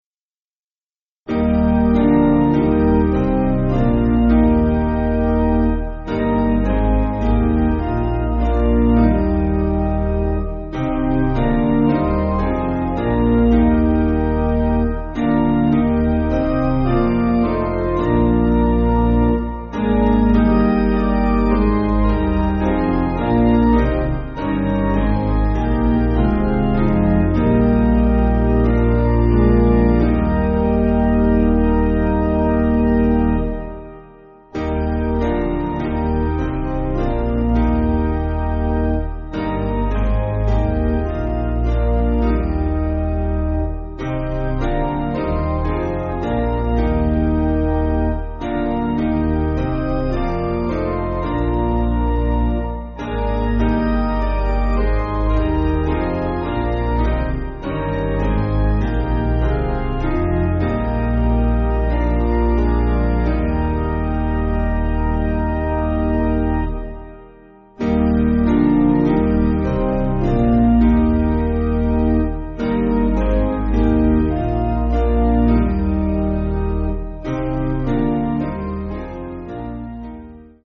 Basic Piano & Organ
(CM)   6/Eb